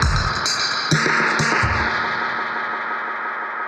Index of /musicradar/dub-designer-samples/130bpm/Beats
DD_BeatFXA_130-03.wav